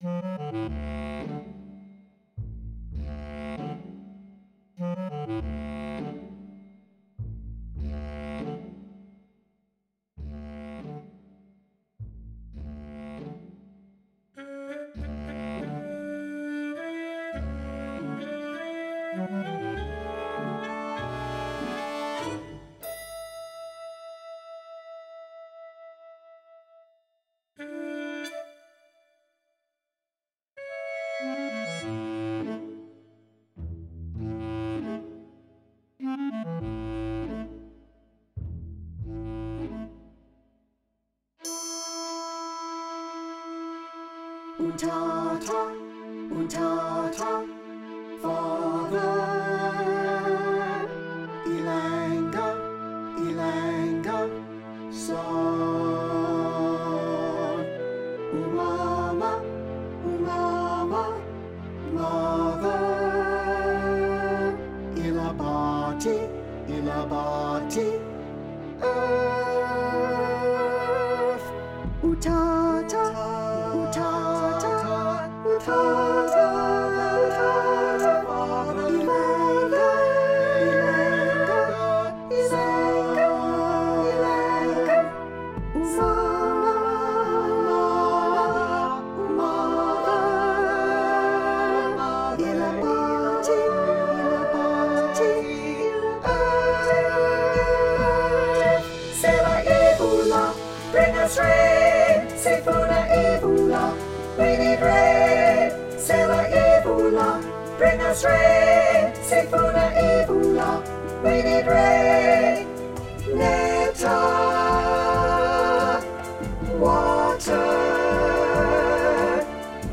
Inspired by the music of South Africa, Rain Dance is a 75-minute, humorous, entirely-sung political drama inspired by Tish Farrell's story, "The Hare Who Would Not Be King".